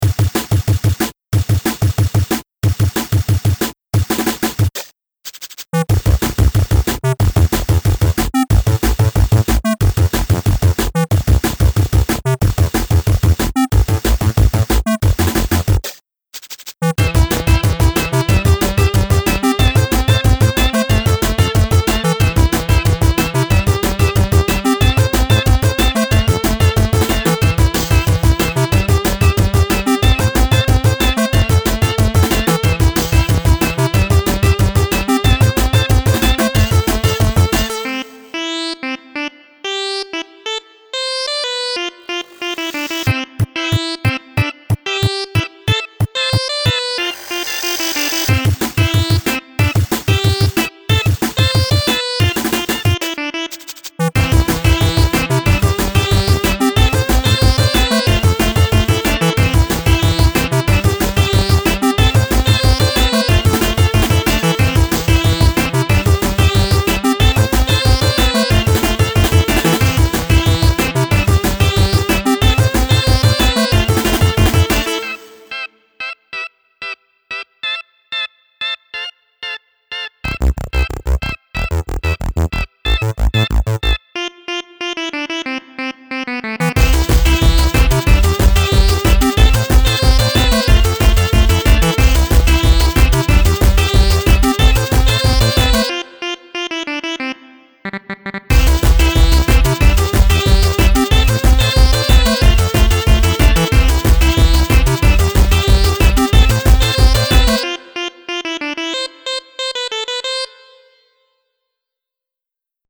Fast fun time 8-bit electronica.